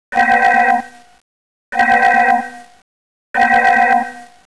Alert
Alert Sound 99 KB
alert.wav